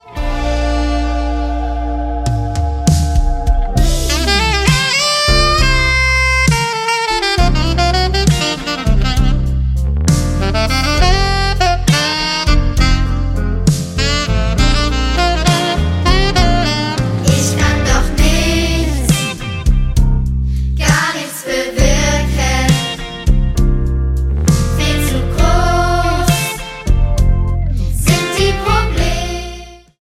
Musical-CD